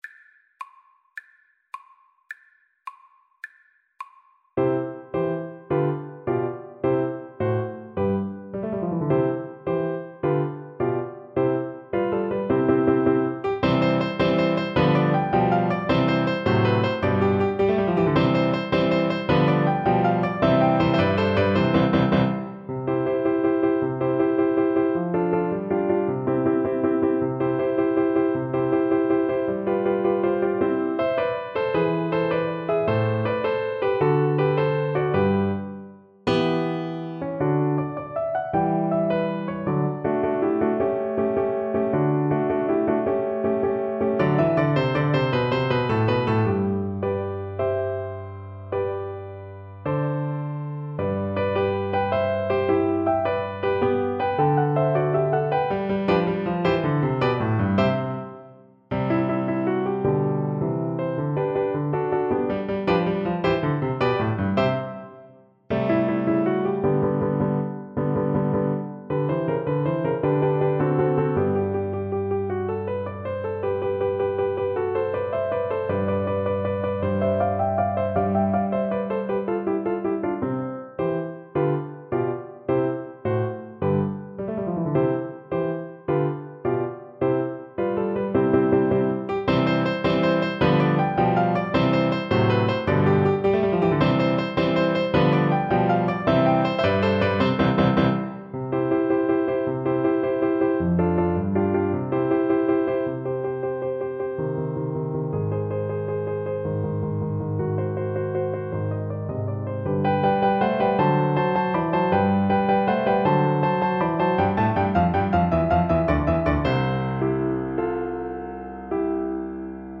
Classical Mozart, Wolfgang Amadeus Horn Concerto No. 4 in Eb 4th movement Soprano (Descant) Recorder version
Recorder
C major (Sounding Pitch) (View more C major Music for Recorder )
6/8 (View more 6/8 Music)
.=106 Allegro vivace (View more music marked Allegro)
Classical (View more Classical Recorder Music)
Joyful Music for Recorder